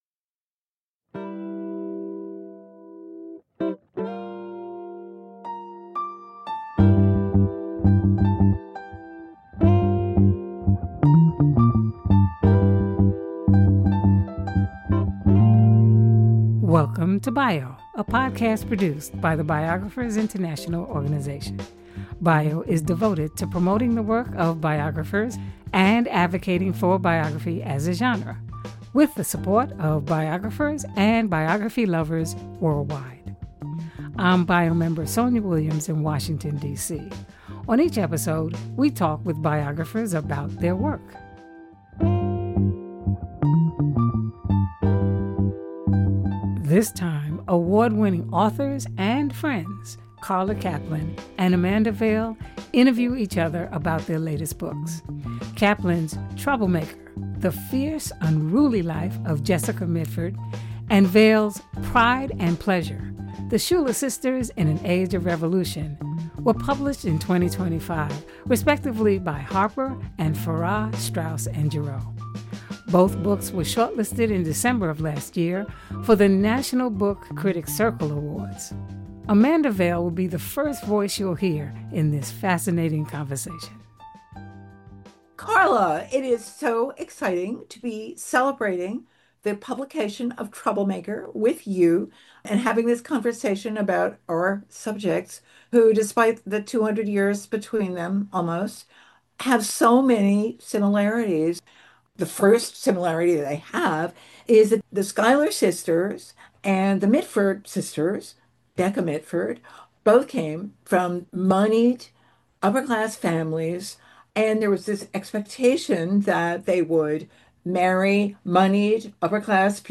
Each week, we post fascinating discussions with biographers from around the country and the world.